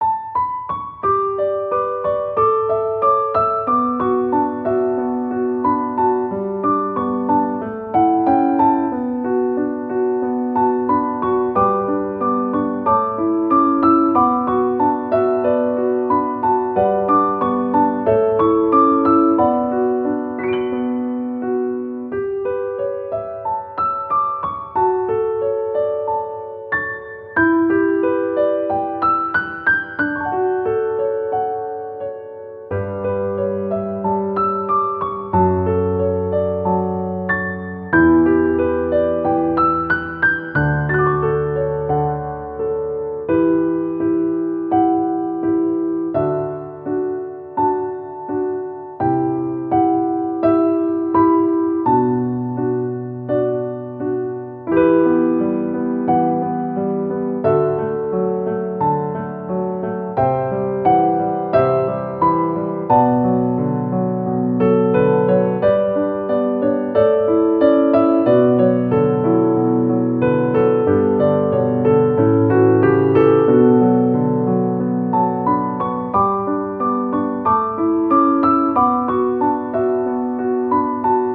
• 暗めのしっとりしたピアノ曲のフリー音源を公開しています。
ogg(L) 楽譜 繊細 切ない 綺麗